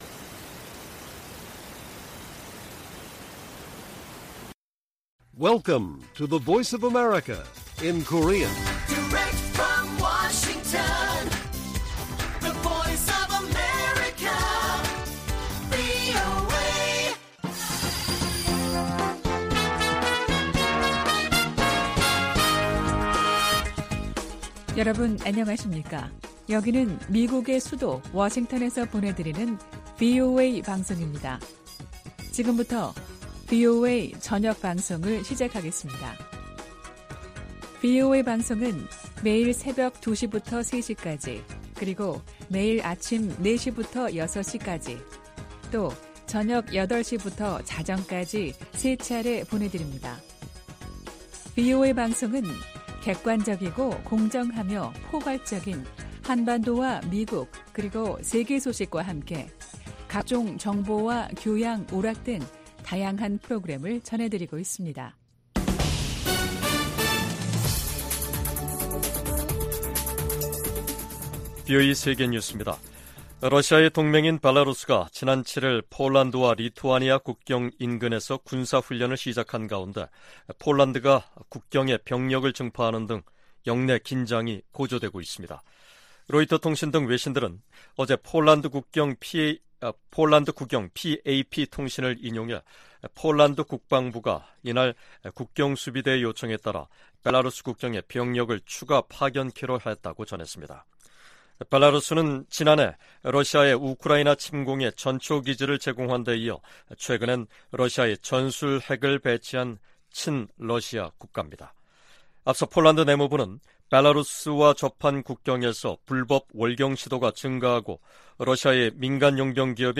VOA 한국어 간판 뉴스 프로그램 '뉴스 투데이', 2023년 8월 9일 1부 방송입니다. 미 국방부는 북한과의 무기 거래는 불법이라며 대가가 따를 것이라고 경고했습니다. 중국 중고 선박이 북한으로 판매되는 사례가 잇따르고 있는 가운데 국무부는 기존 대북제재를 계속 이행할 것이라는 입장을 밝혔습니다. 다음 주 미국에서 열리는 미한일 정상회의를 계기로 3국 정상회의가 정례화될 가능성이 높다고 미국 전문가들이 전망했습니다.